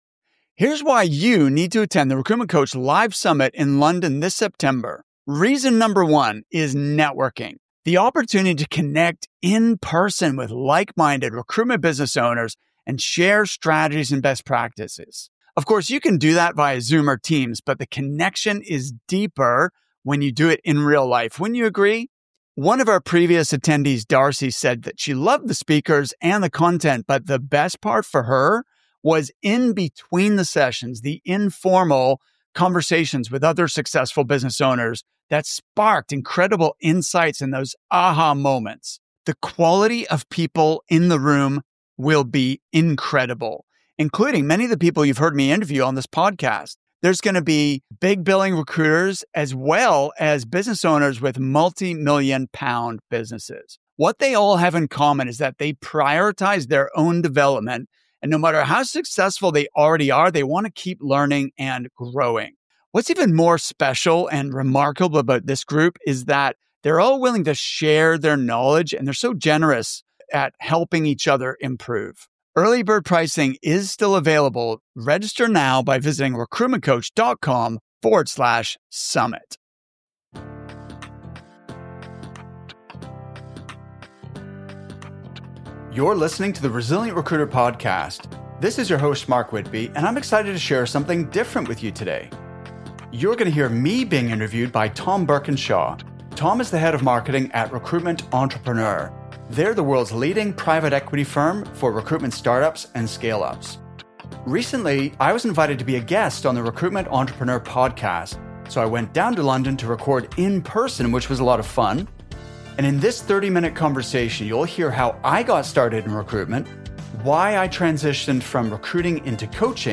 James Caan’s team invited me to London to record an in-person interview for The Recruitment Entrepreneur Podcast.